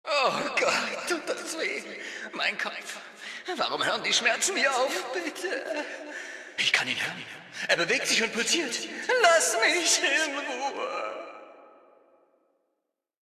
Datei:Maleadult01default freeformdc ffdcadamsmorgan 000cb523.ogg
Fallout 3: Audiodialoge